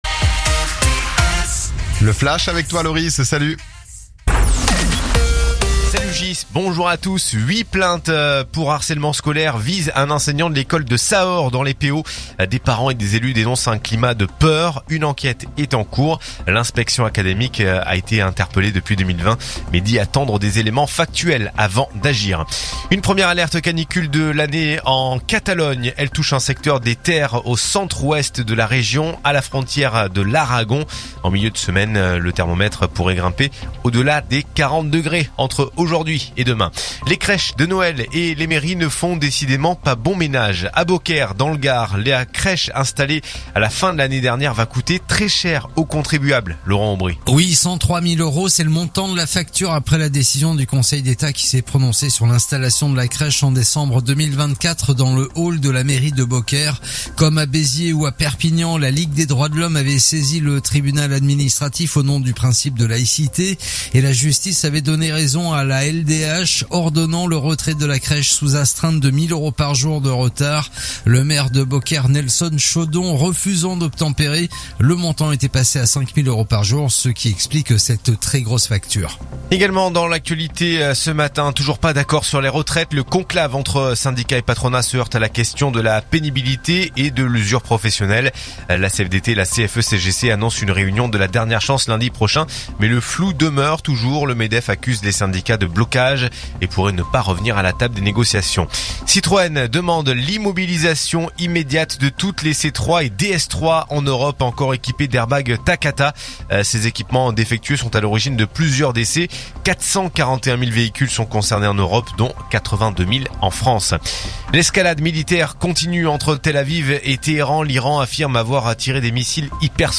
info_perpignan_418.mp3